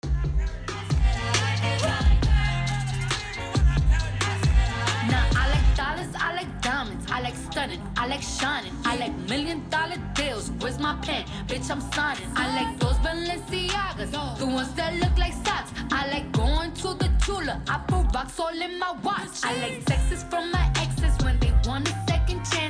Petit enregistrement sonore capté avec un iPhone 7. De base le fichier était en .m4a (1,1 Mo) que j’ai du compresser en .mp3 (donc il y a eu de la perte mais c’est mieux que rien) pour que vous puissiez écouter.